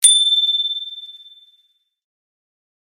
bicycle-bell_01
bell bells bicycle bike bright chime chimes clang sound effect free sound royalty free Memes